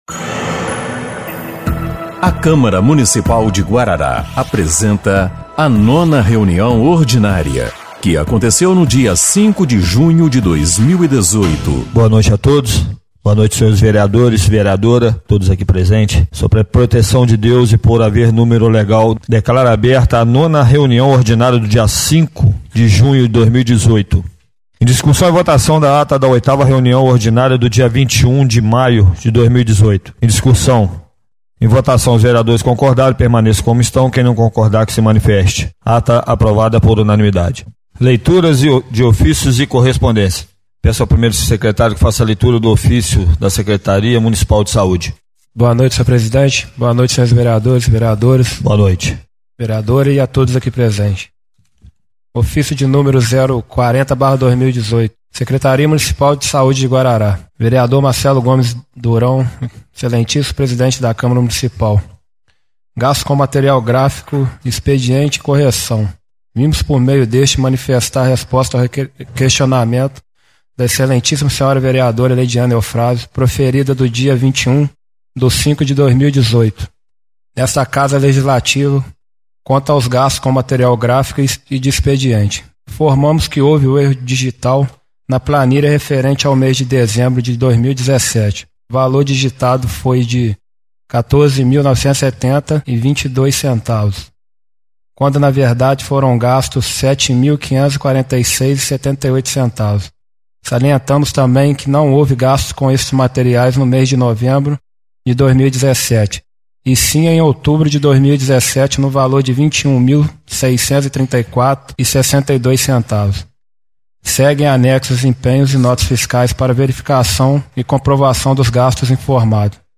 9ª Reunião Ordinária de 05/06/2018